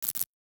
NOTIFICATION_Subtle_03_mono.wav